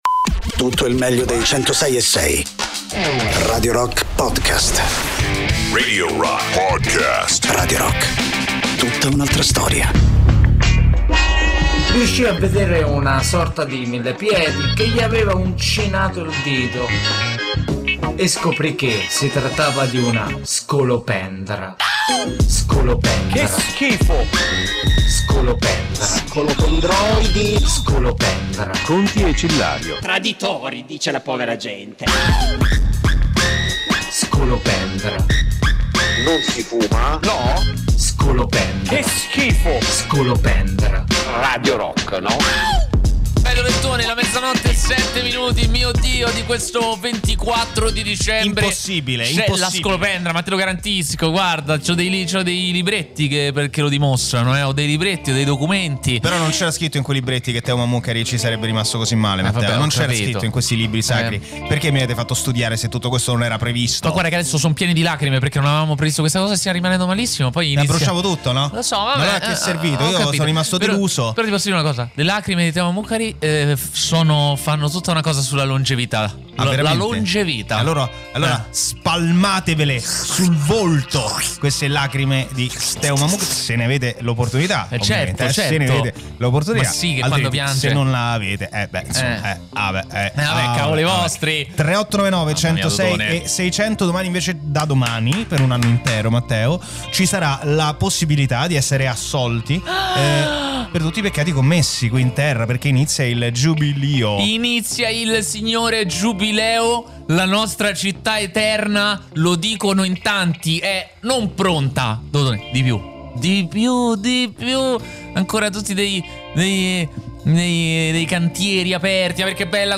in diretta da Lunedì a Giovedì da mezzanotte all’1